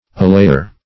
Allayer \Al*lay"er\, n.